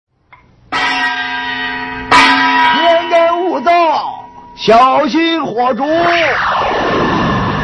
天干物燥小心火烛音效_人物音效音效配乐_免费素材下载_提案神器